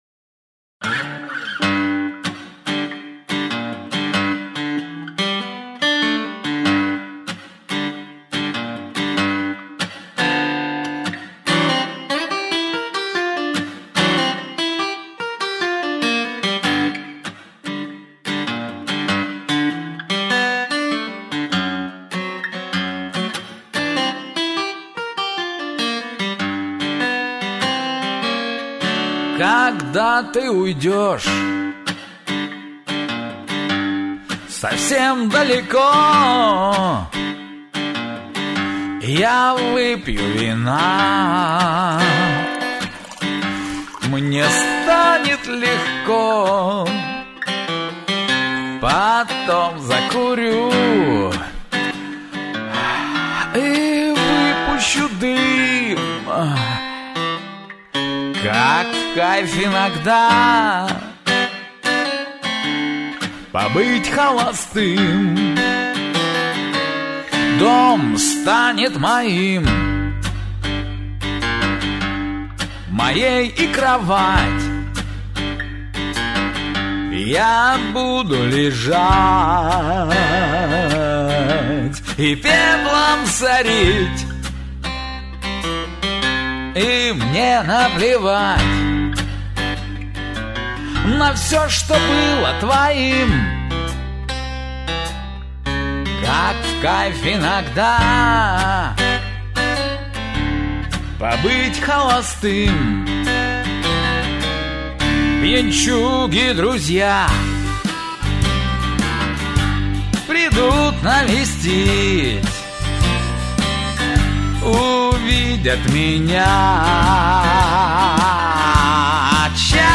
«BLUES на русском»